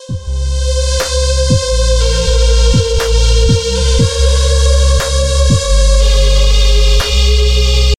Помогите накрутить PAD
Насколько знаю автор делал его не сам а взял из какого то сборника,синт Massive или Serum) Спасибо заранее